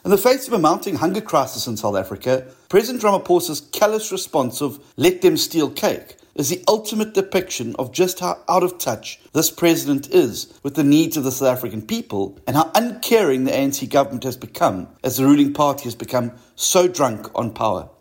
Steenhuisen sê dit kom neer op net onder 600-honderd-duisend-rand in totaal vir minder as 18 BBP-gaste, wat president Cyril Ramaphosa en die ANC se blatante minagting vir belastingbetalers demonstreer:
ENG-SteenhuisenOnExpenditure.mp3